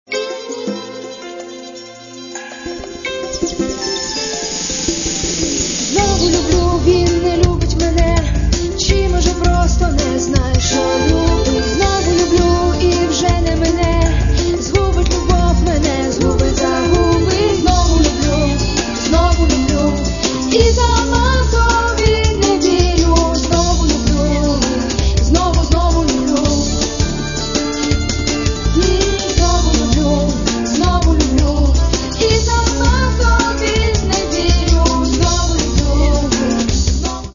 Каталог -> MP3-CD -> Альтернатива